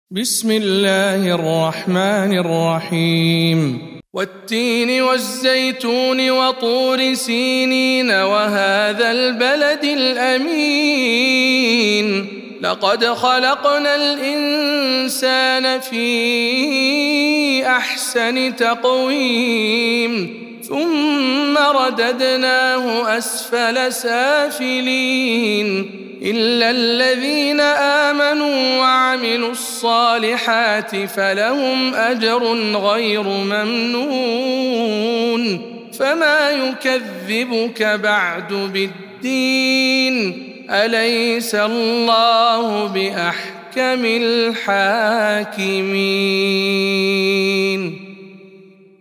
سورة التين - رواية إسحاق عن خلف العاشر